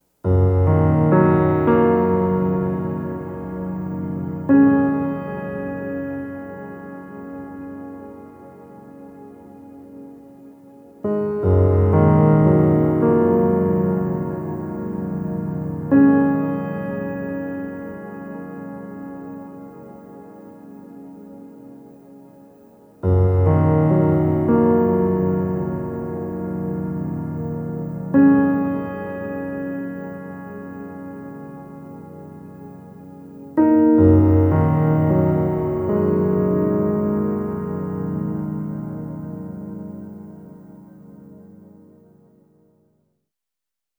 Treated Piano 02.wav